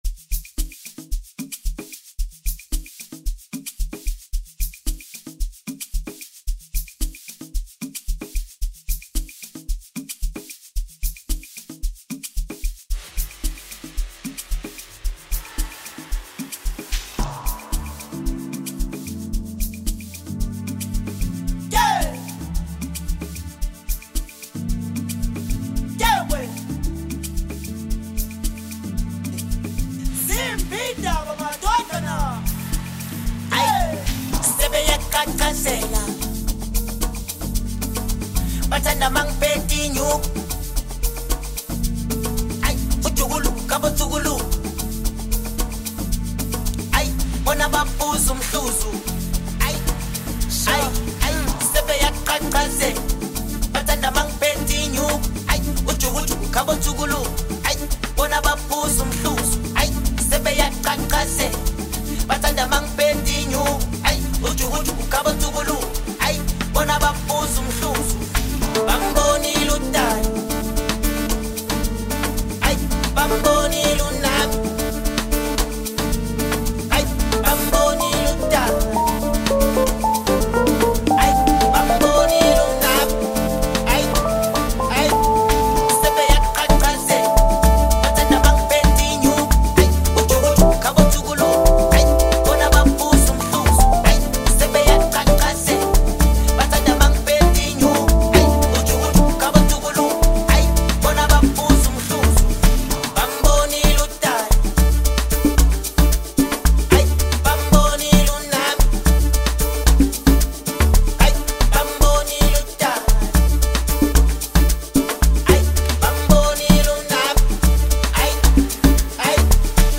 Home » South African Music